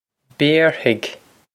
Béarfaidh Bare-hig
This is an approximate phonetic pronunciation of the phrase.